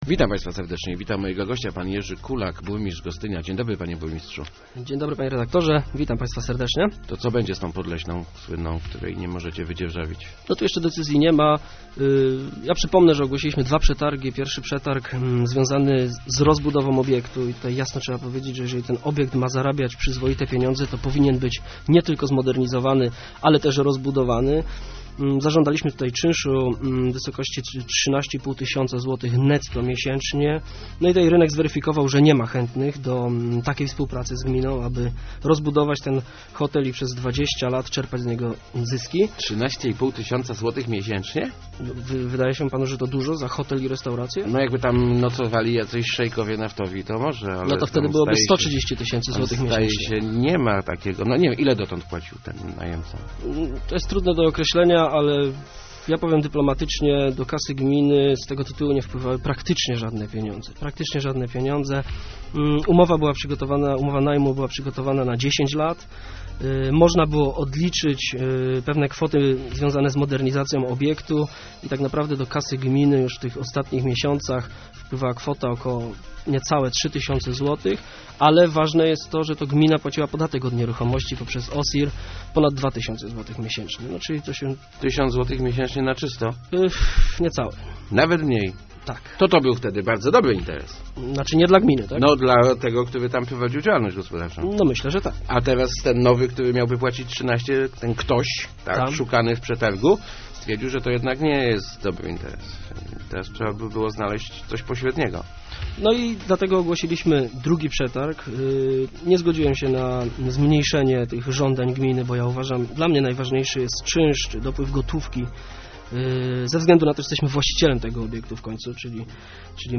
Kompleks Podleśna wejdzie w skład spółki komunalnej - zapowiadał w Rozmowach Elki burmistrz Gostynia Jerzy Kulak. W ten sposób, jego zdaniem, można zmniejszyć koszty utrzymania MOSiRu, który kosztuje gminę 4 miliony złotych rocznie.